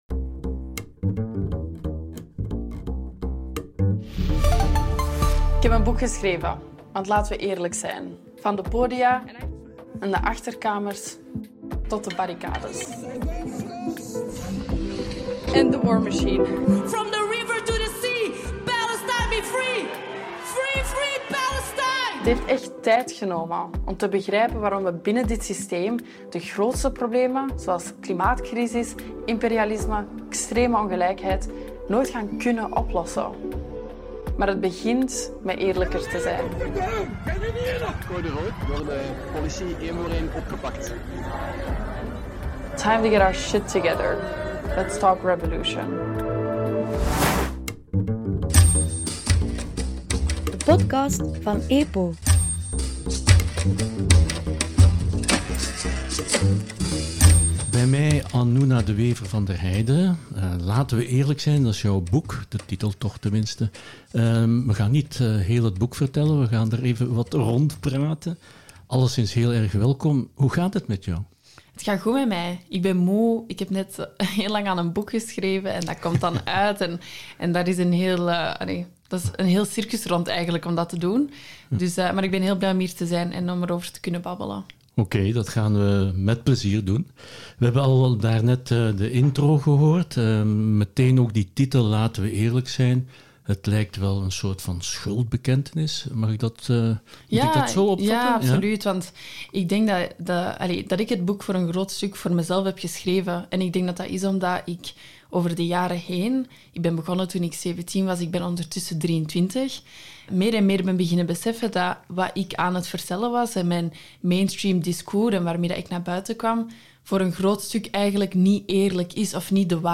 Een boeiend gesprek waarin Anuna de luisteraar meeneemt in een diepere analyse van het systeem waarin we leven en hoe we - door eerlijker te durven zijn - iets revolutionairs kunnen bouwen.